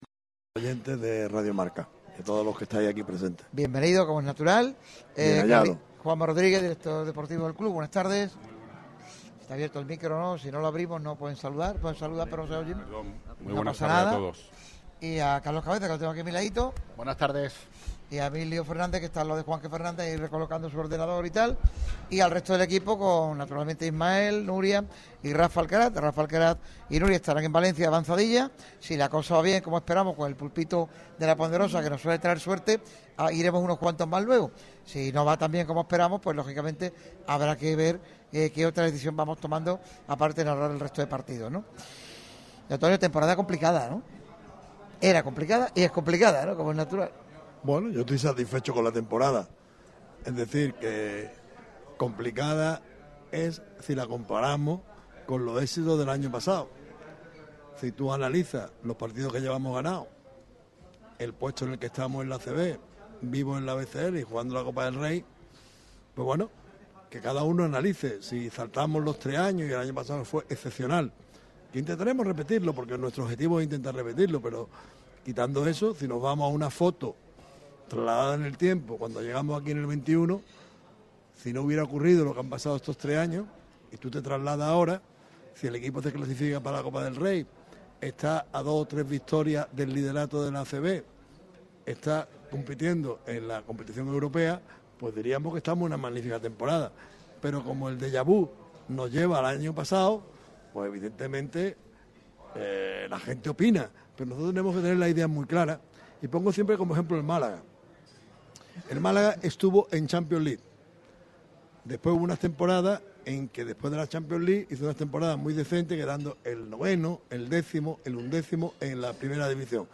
Día especial en la previa de la Copa ACB en La Ponderosa Playa.
han estado presentes en una entrevista en exclusiva en la radio del deporte.